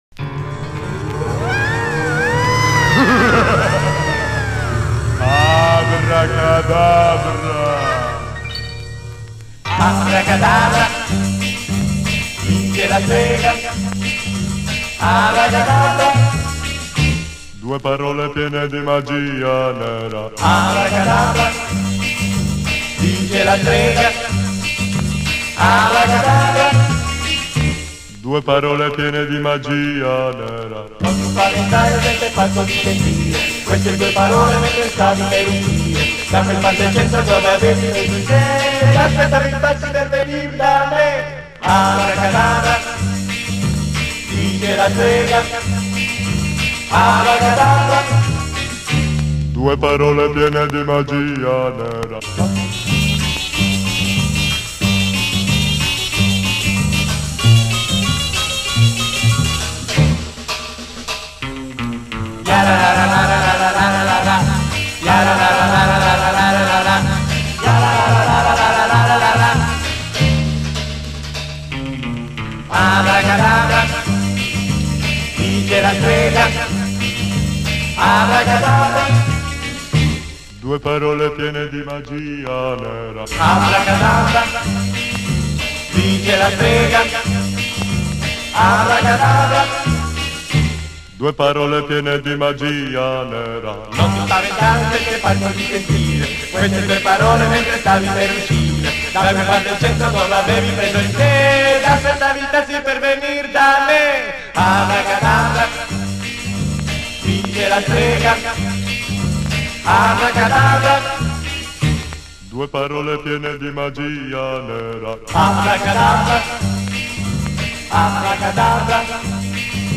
Моно